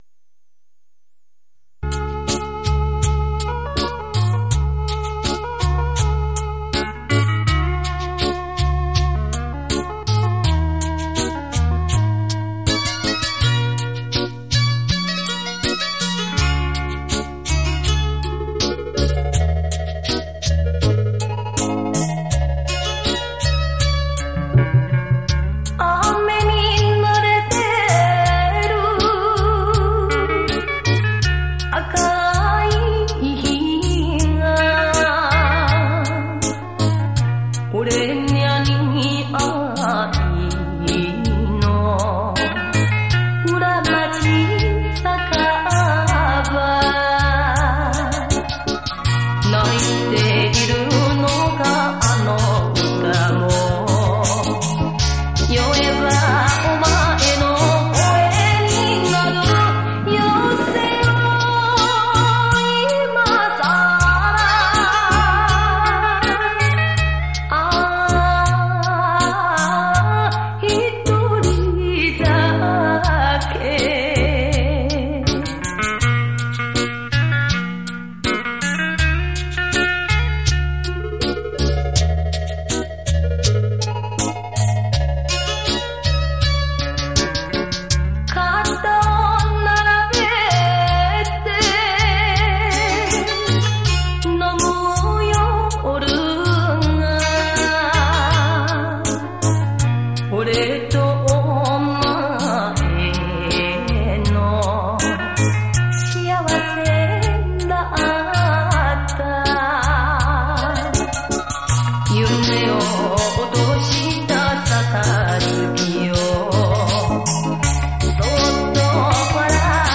黄金双电脑鼓·特殊效果伴奏